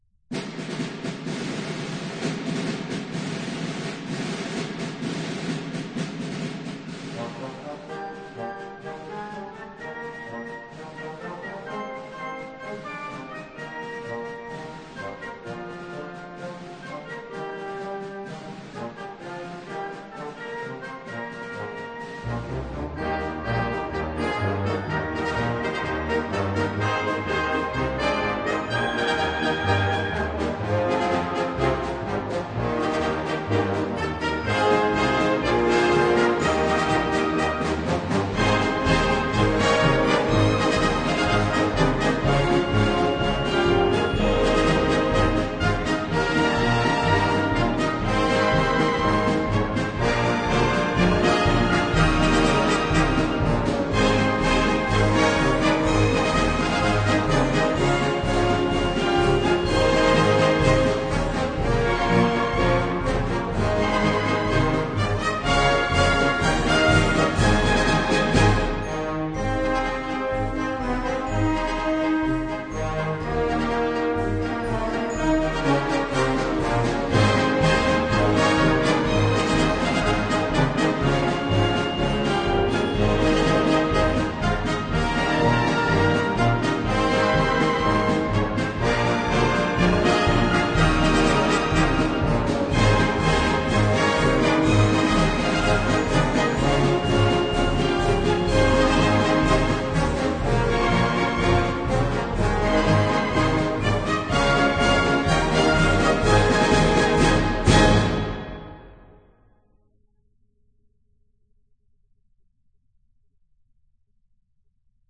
本片收录有摇篮曲、古典小品、电视和电影歌曲、百老汇音乐，甚至卡通音乐都收录其中。